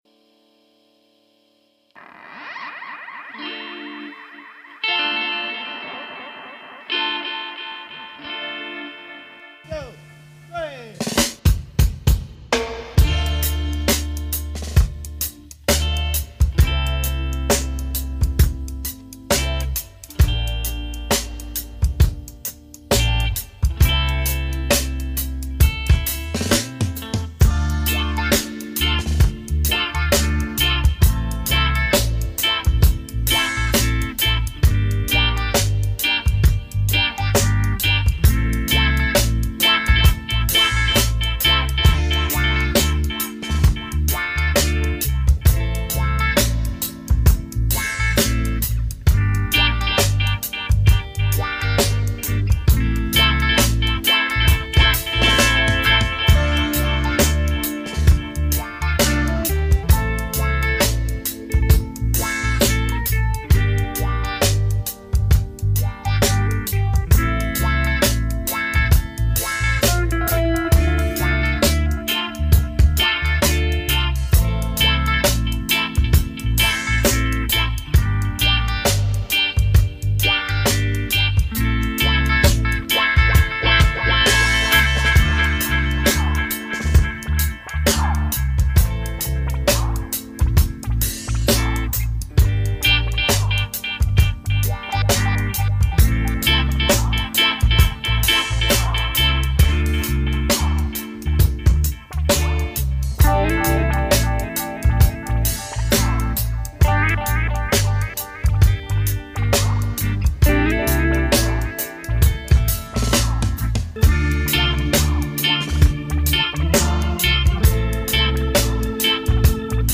Live Instrumental Dubplate.